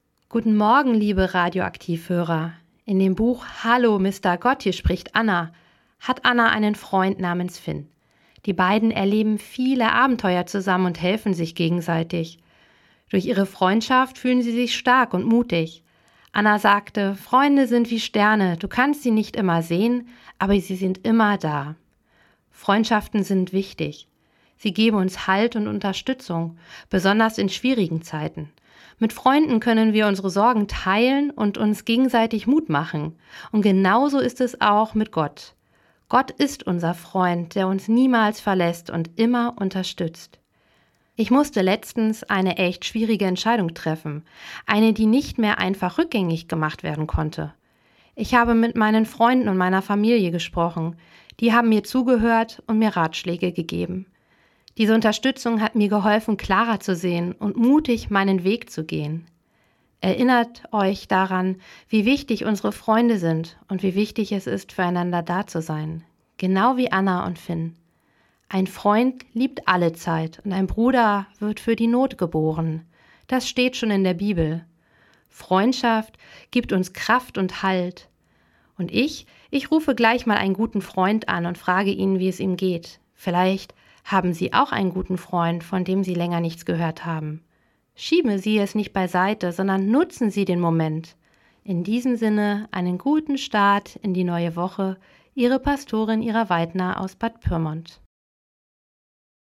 Radioandacht vom 23. Juni